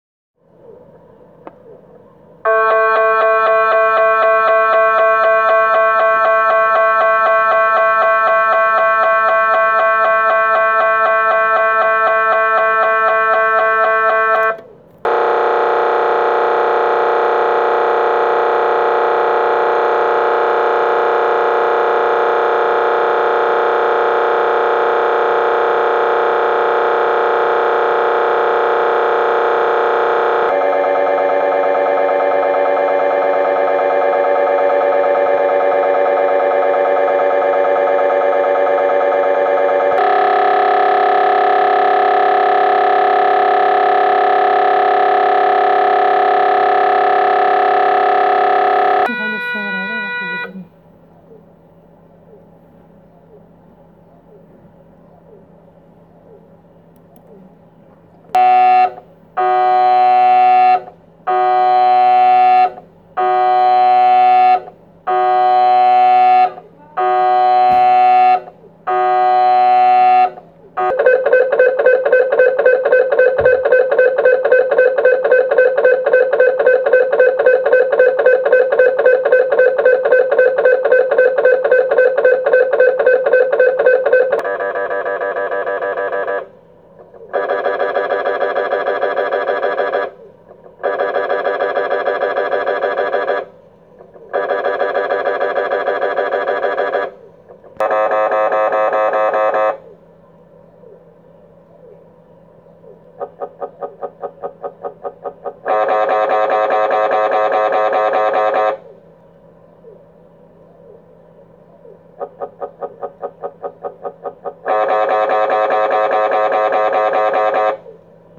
De scanner maakt wel heel veel herrie.
Luister hier het geluidsfragment, dan weet je welke geluiden je tijdens de MRI zou kunnen horen.
mri-geluid.m4a